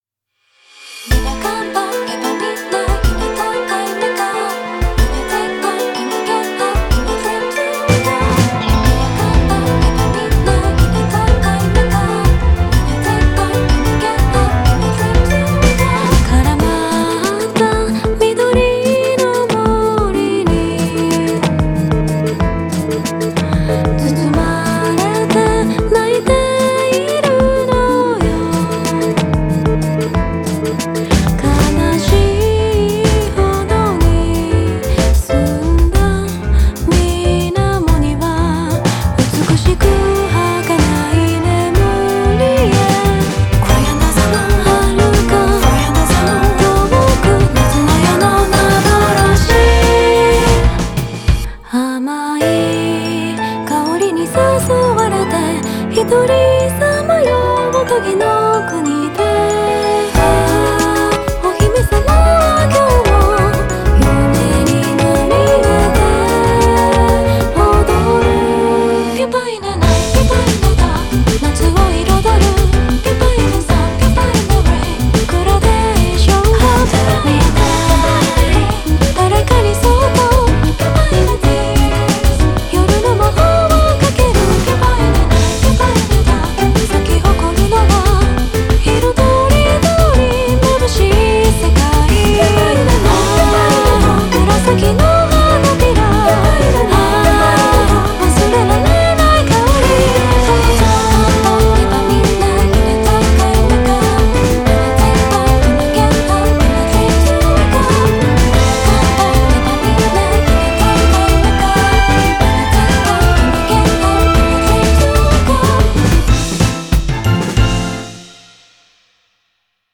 BPM93-186
vocal arrange
It's a chill fairy-like song.